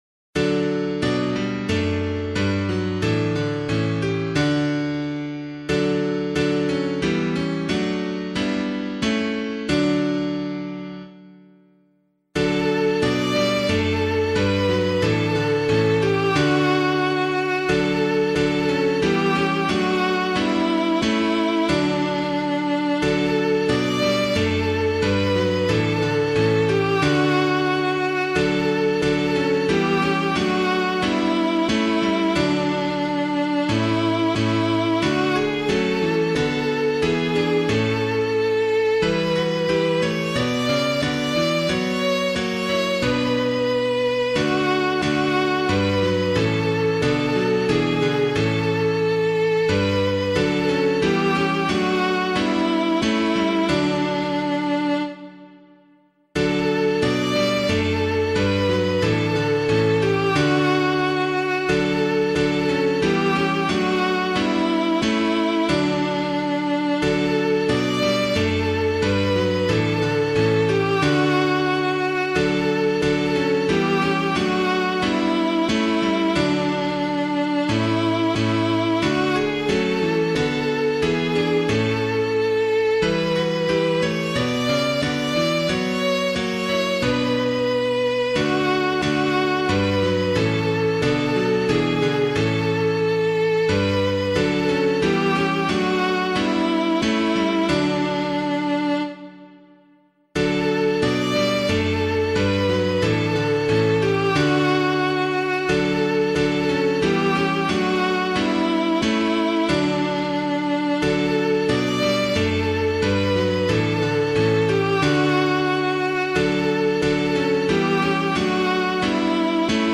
Introit hymn suitable for Catholic liturgy
061 Ordinary Time 27 Introit [Tietze - SALZBURG] - piano.mp3